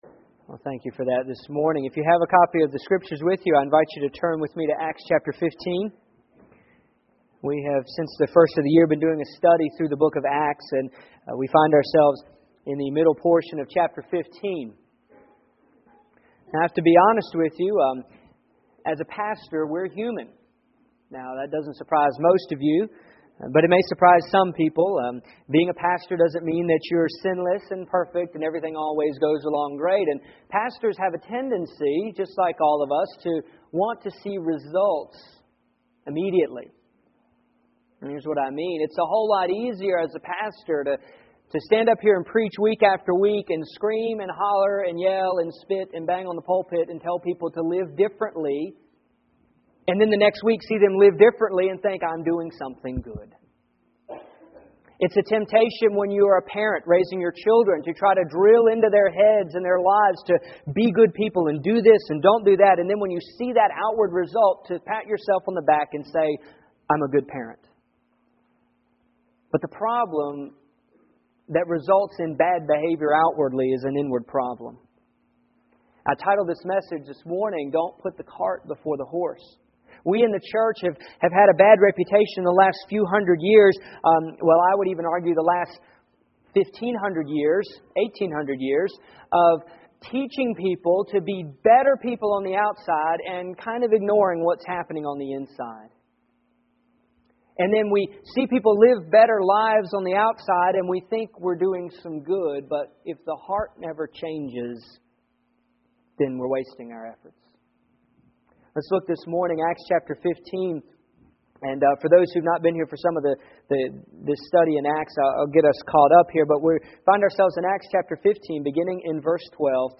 Here is my sermon from yesterday